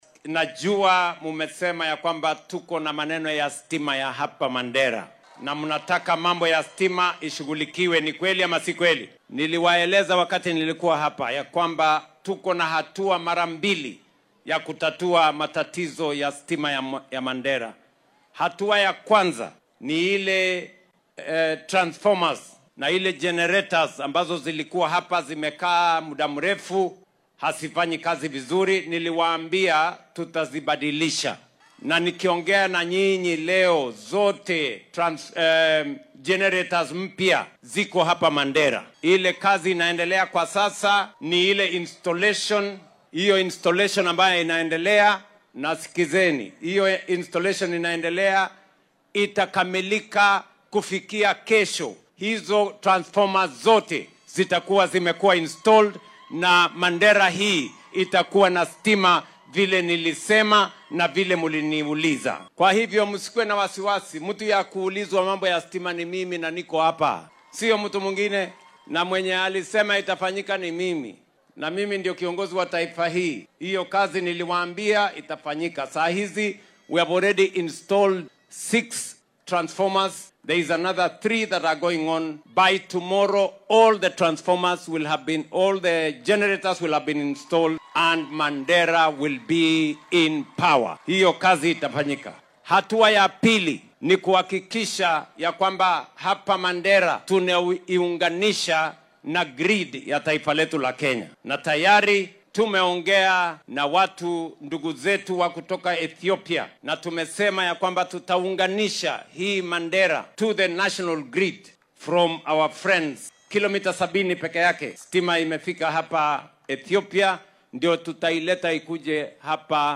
DHEGEYSO:Madaxweyne Ruto oo ka hadlay koronto la’aanta ka jirta Mandera